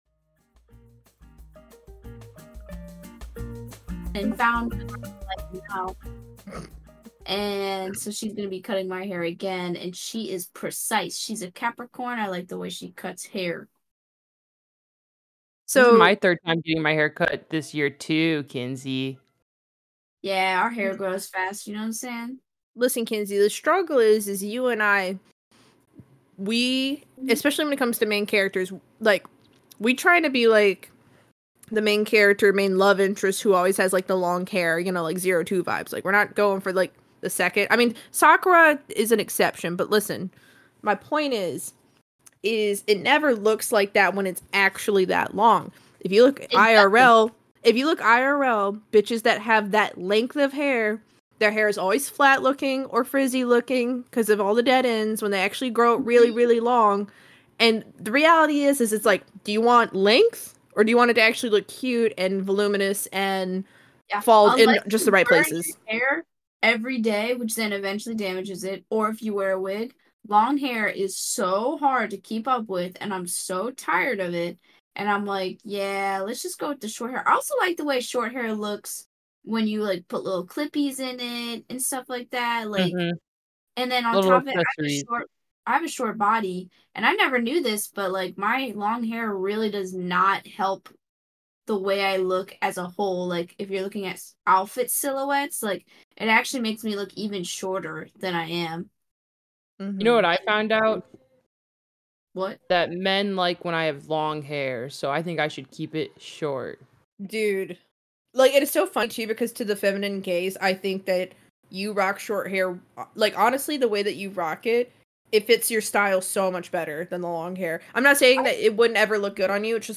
Podcast where 3 friends shoot the shit and escape the world by sharing and indulging in new media content every week :0 We all know the feeling that exists at the end of your new favorite movie, video game, or tv show.